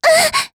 s028_Impact_Hit.wav